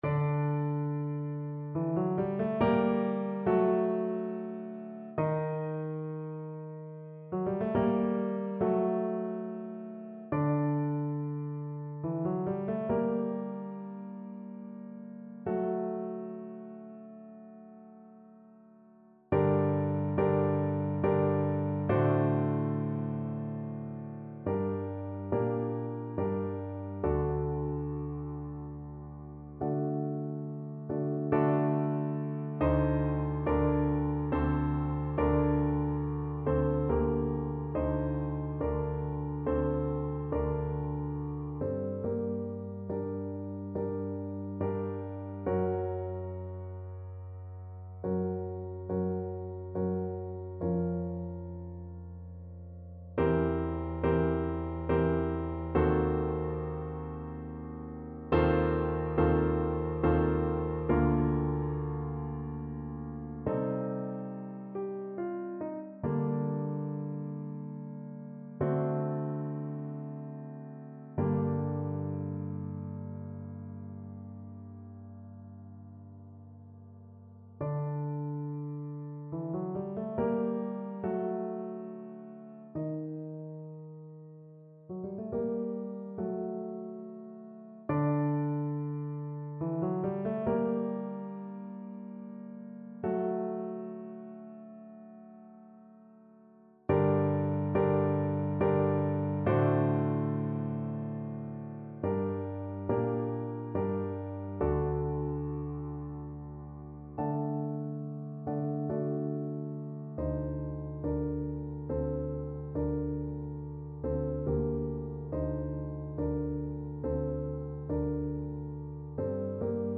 Trumpet version
Ziemlich langsam = 70 Ziemlich langsam
3/4 (View more 3/4 Music)
G4-Eb6
Classical (View more Classical Trumpet Music)